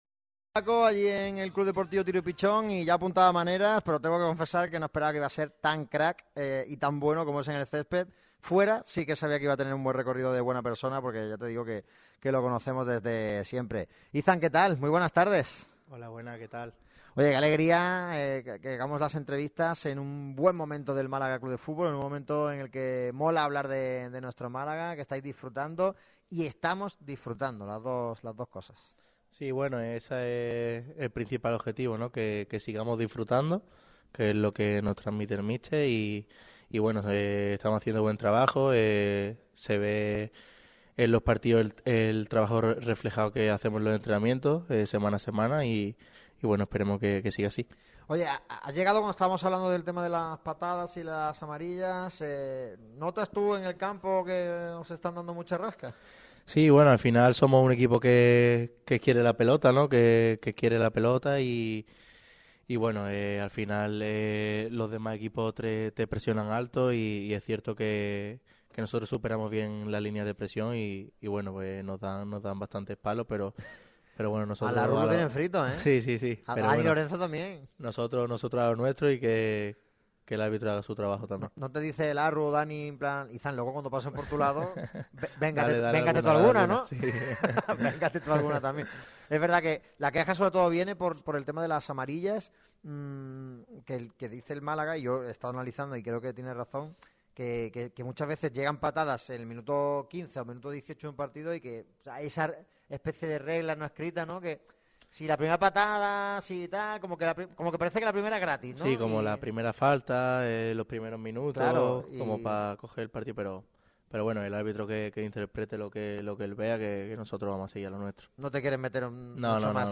ha atendido a Radio MARCA Málaga en una entrevista exclusiva este jueves.